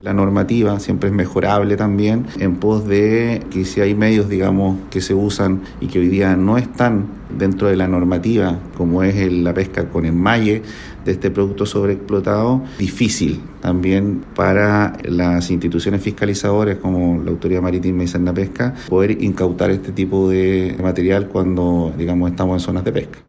Trabajo que están realizando, indicó el director (s) de Sernapesca Los Lagos, Branny Montecinos, pero en un contexto en que hay ciertas condiciones, de tipificación de acciones, que limitan el actuar de las instituciones.